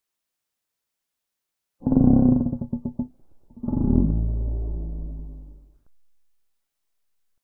mario paint baby slow
mario-paint-baby-slow.mp3